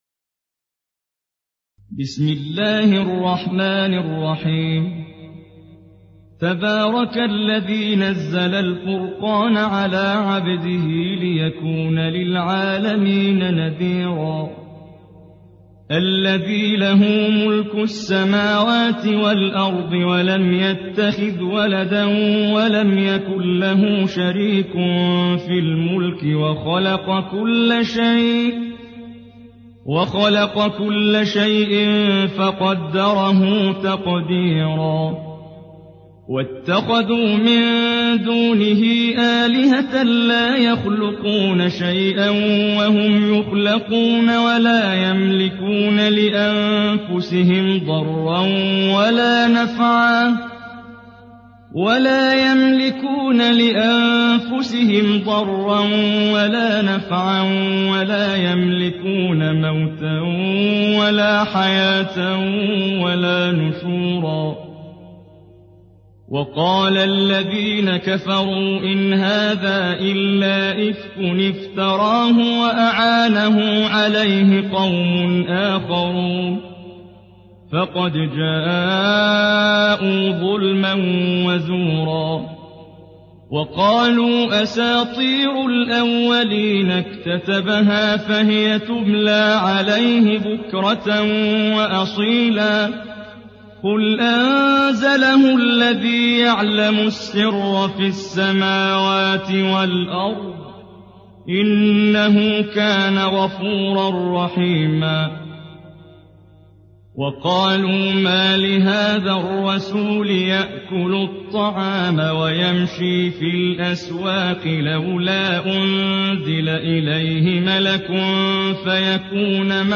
Surah আল-ফুরক্বান MP3 by Muhammad Jibreel in Hafs An Asim narration.
Murattal Hafs An Asim